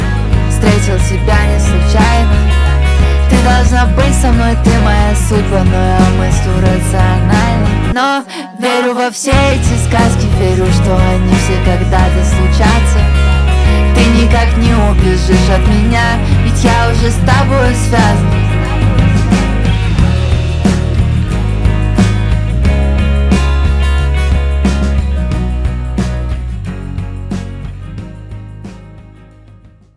Жанр: Альтернатива / Русские